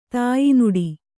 ♪ tāyi nuḍi